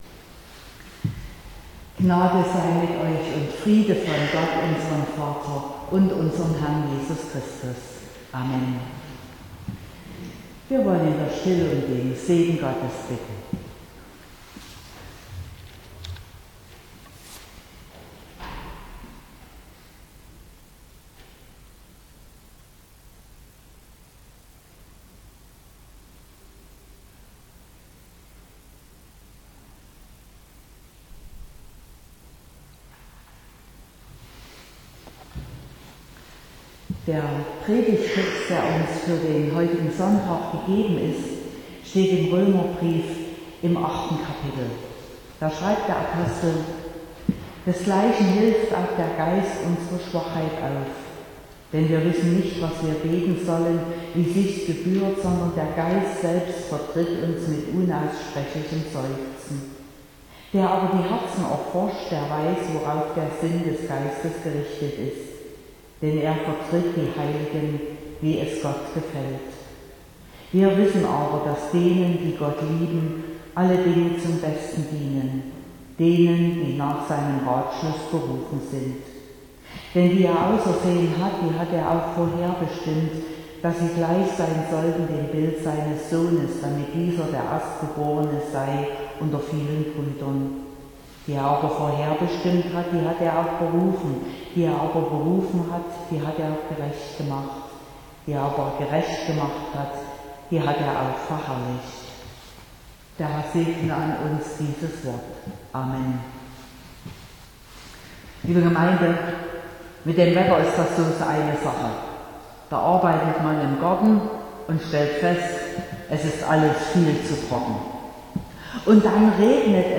29.05.2022 – Gottesdienst
Predigt und Aufzeichnungen